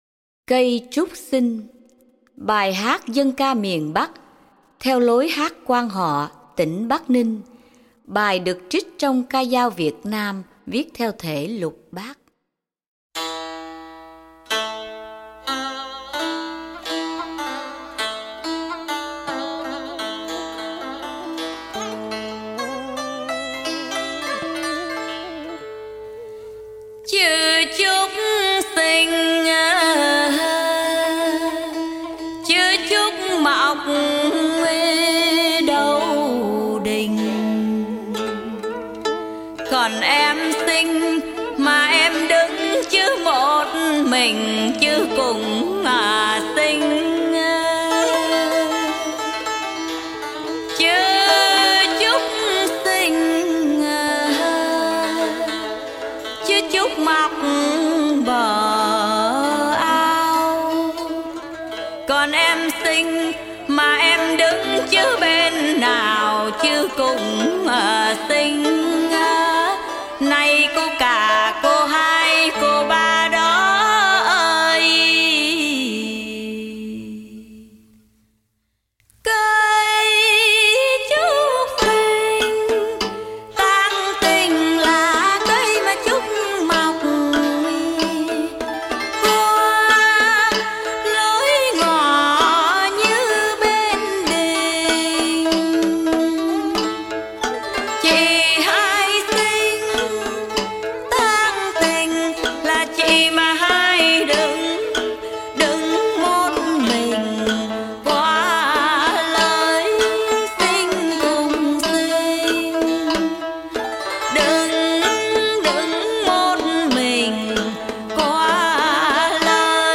“Cây Trúc Xinh” – Dân ca do Phạm Duy sưu tập.
Trình bày: Hoàng Oanh